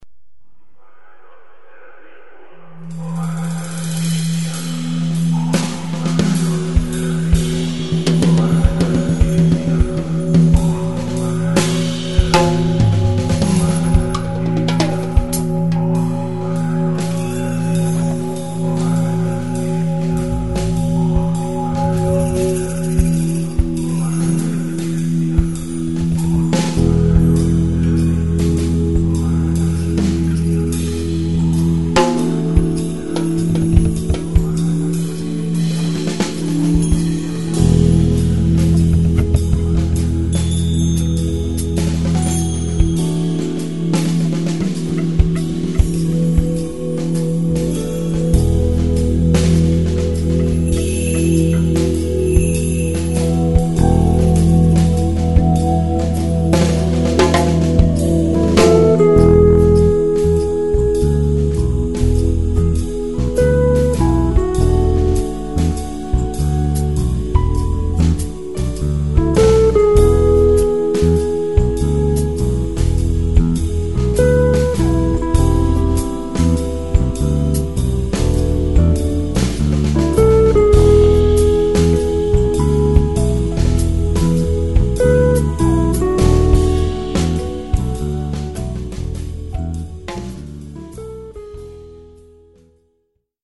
batteria, percussioni, voci
piano, tastiere, voci
chitarre, voci
basso, percussioni, charango, voci
notturno, sommesso nelle note delle tastiere